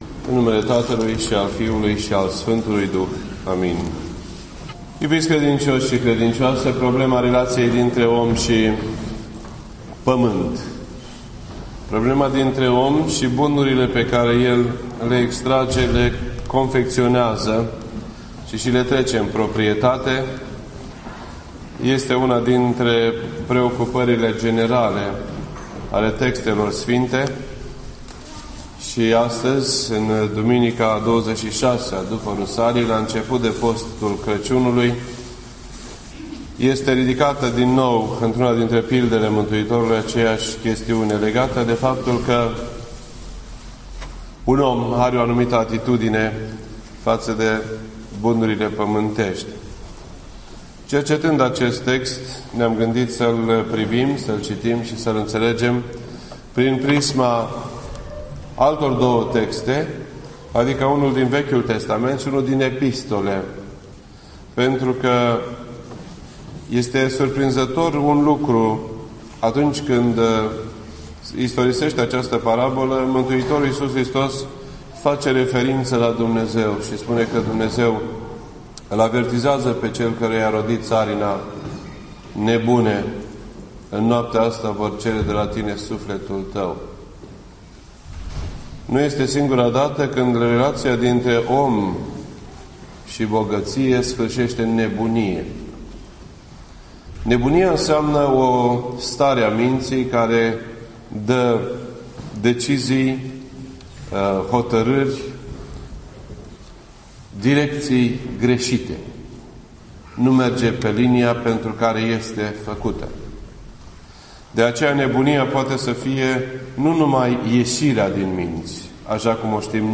This entry was posted on Sunday, November 18th, 2012 at 8:25 PM and is filed under Predici ortodoxe in format audio.